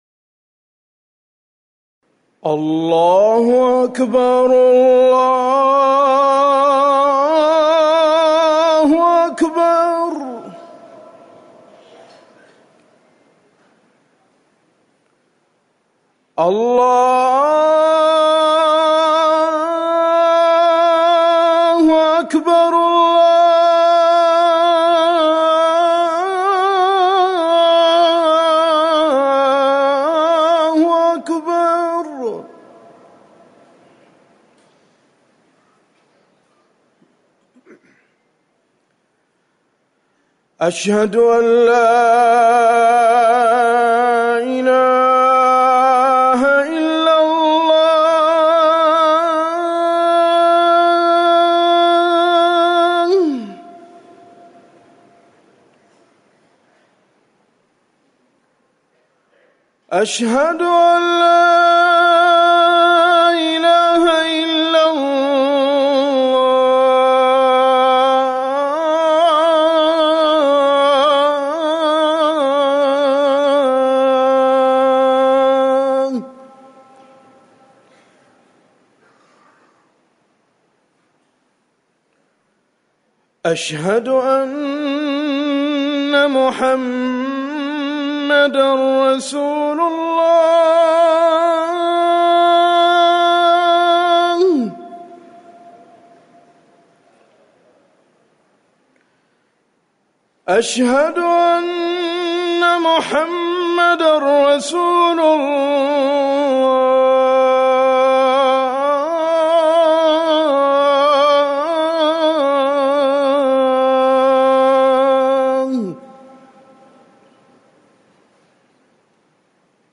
أذان العصر
تاريخ النشر ١٠ صفر ١٤٤١ هـ المكان: المسجد النبوي الشيخ